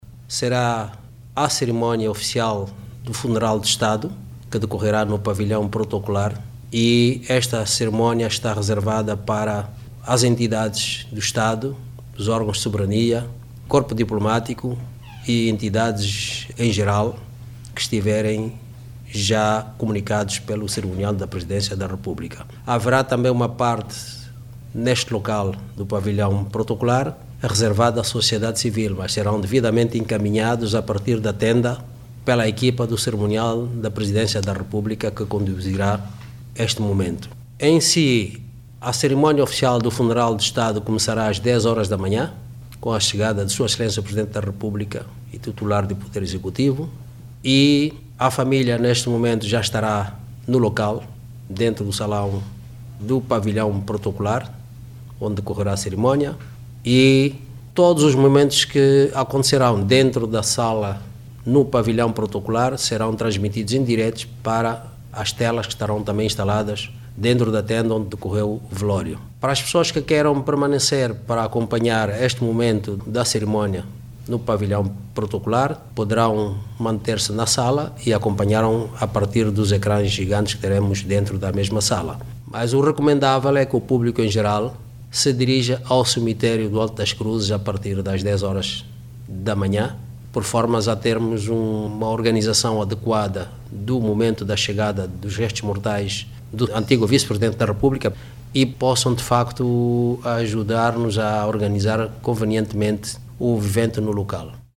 O Ministro de Estado e Chefe da Casa Militar do Presidente da República e Coordenador da Comissão das Exéquias, Francisco Pereira Furtado, traça o cronograma das actividades que culminam com o funeral.
FRANCISCO-PEREIRA-FURTADO-1-07HRS.mp3